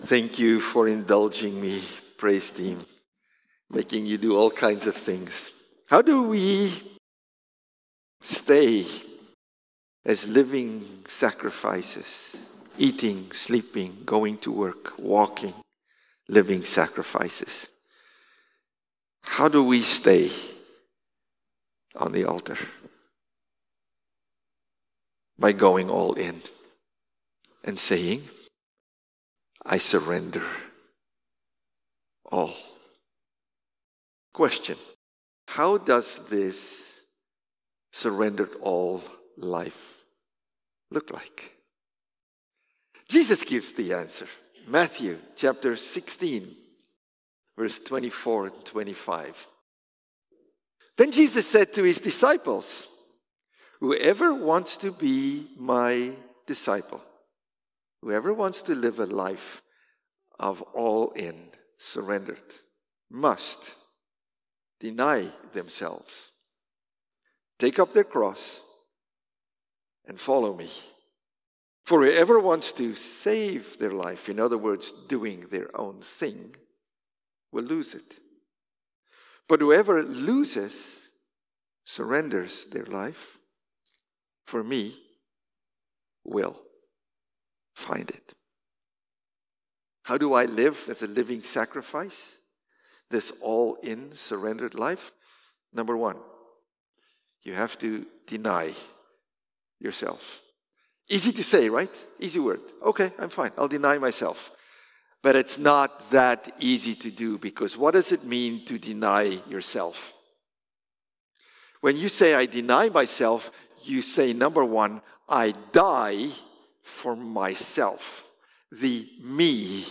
November-24-Sermon.mp3